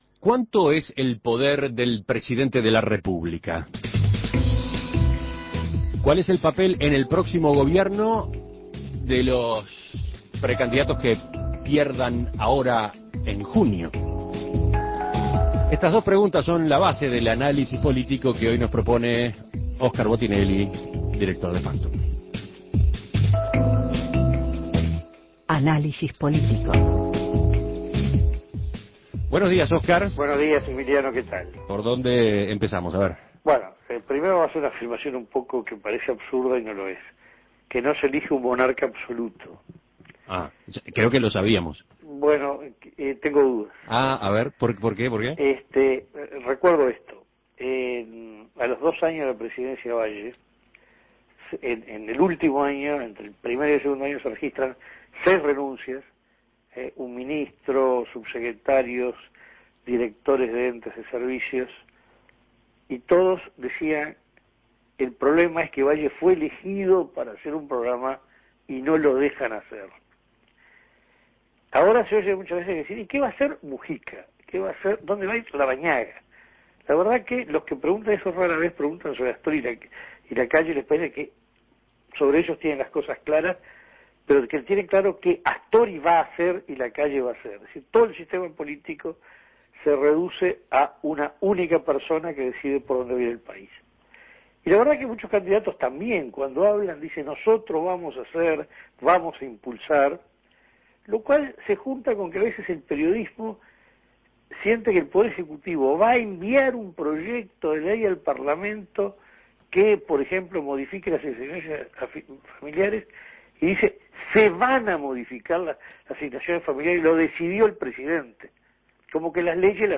Análisis Político ¿Cuánto es el poder del presidente de la República?